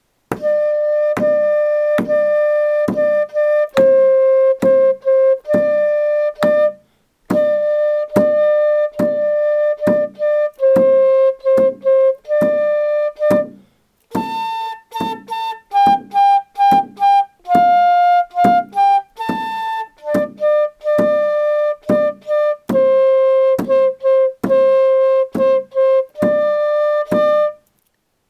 Chant Melody audio (no words)
forseti_chant.mp3